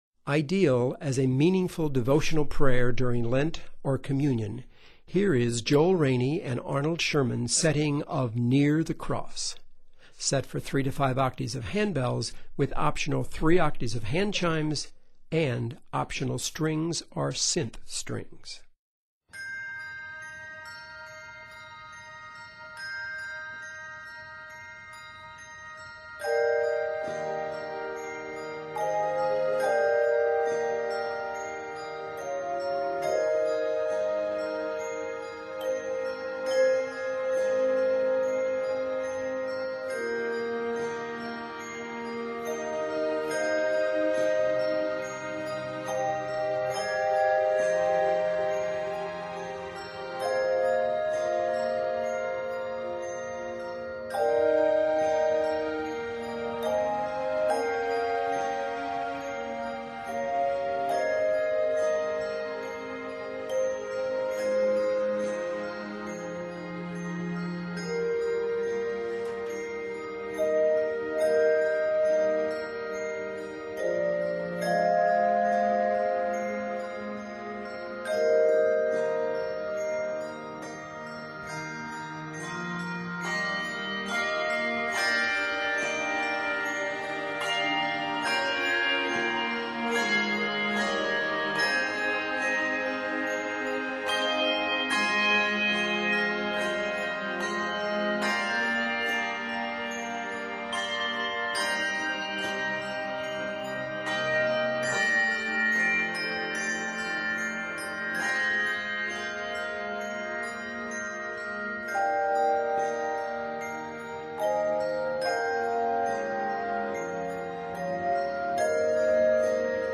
3-5 octaves handbells
set in Eb Major